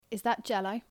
/j/ yacht versus / dʒ / jot